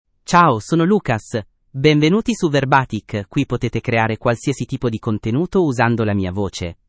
LucasMale Italian AI voice
Lucas is a male AI voice for Italian (Italy).
Voice sample
Listen to Lucas's male Italian voice.
Lucas delivers clear pronunciation with authentic Italy Italian intonation, making your content sound professionally produced.